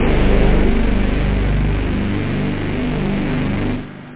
Amiga 8-bit Sampled Voice
303-frequencestart.mp3